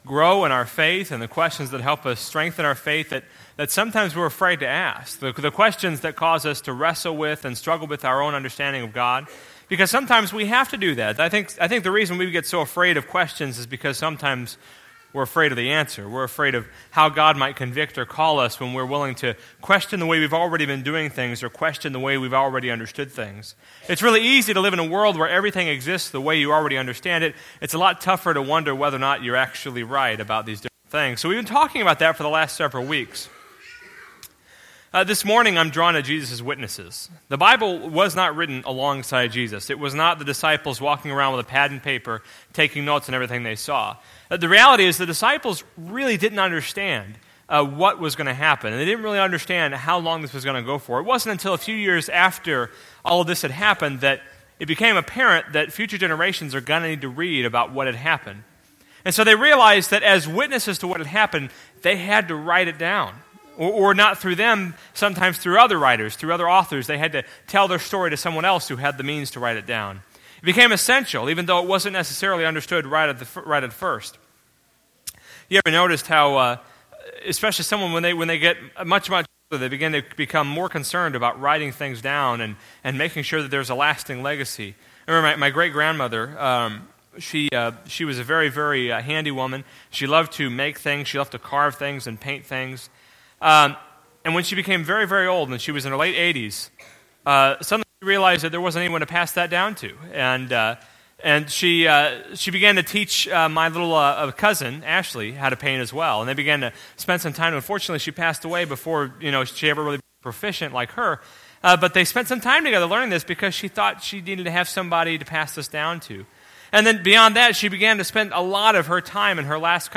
Fearless/The Courage to Question Service Type: Sunday Morning « Fearless